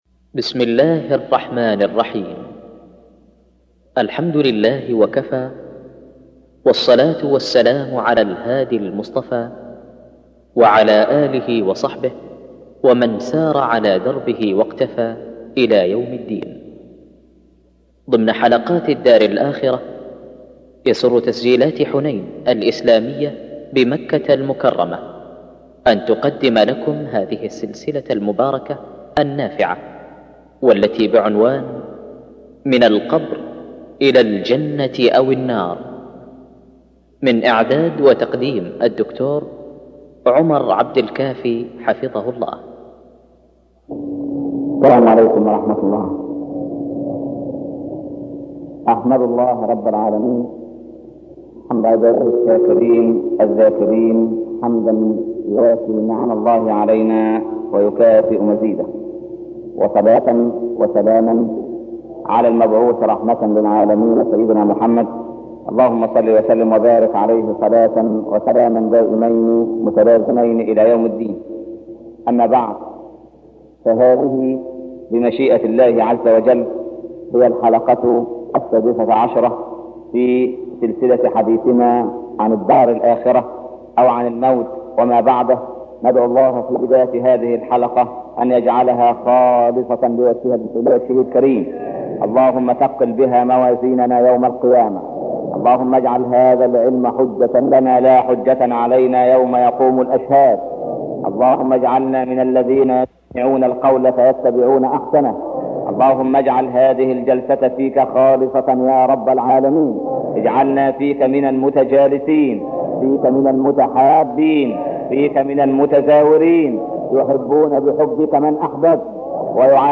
عنوان المادة الدرس السادس عشر - الدار الآخرة تاريخ التحميل الخميس 7 نوفمبر 2013 مـ حجم المادة 34.12 ميجا بايت عدد الزيارات 989 زيارة عدد مرات الحفظ 588 مرة إستماع المادة حفظ المادة اضف تعليقك أرسل لصديق